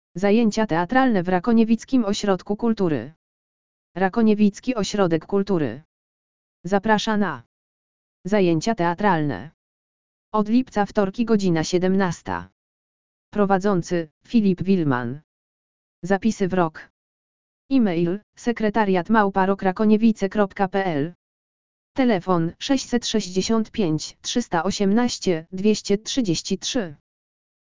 lektor_audio_zajecia_teatralne_w_rakoniewickim_osrodku_kultury.mp3